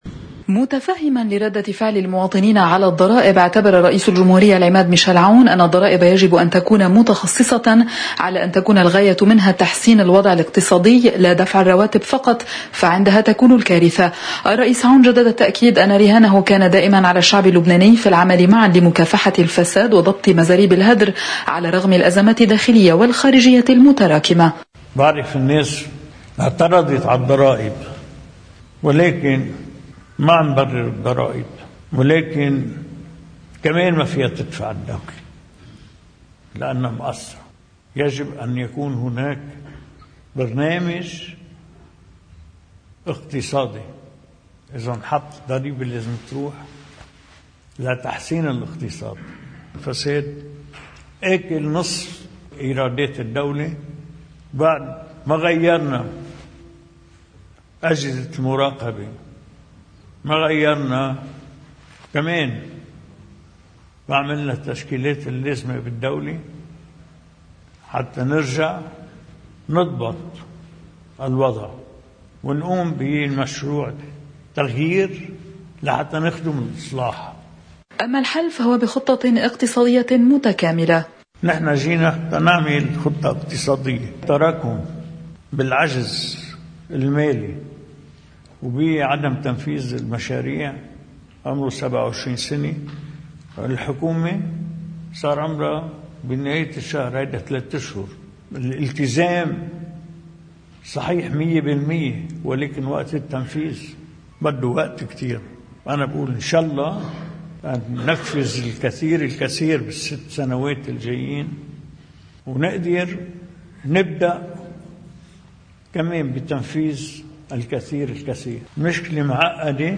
الرئيس عون أمام زواره: أتفهّم ردّة فعل المواطنين على الضرايب، ومما قاله: